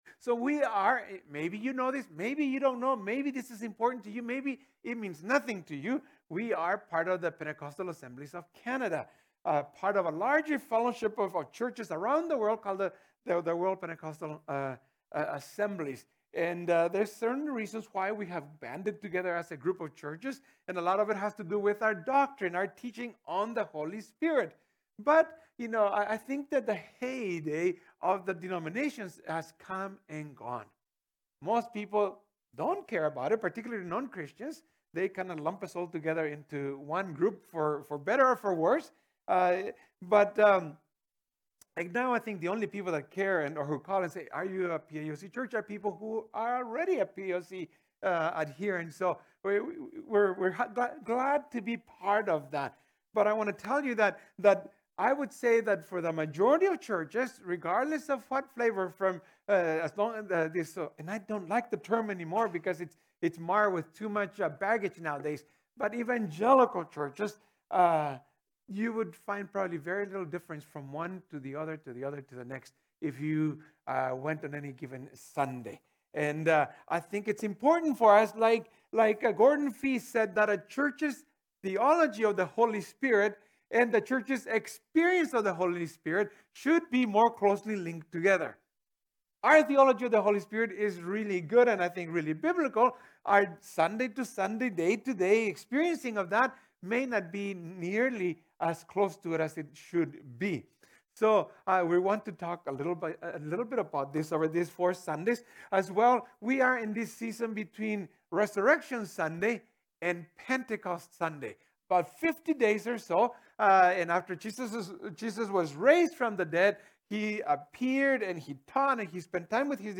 This sermon will help us understand who the Holy Spirit is and offers three practical steps for a more intimate relatioship with the God the Holy Spirit so we can live Spirit-led lives.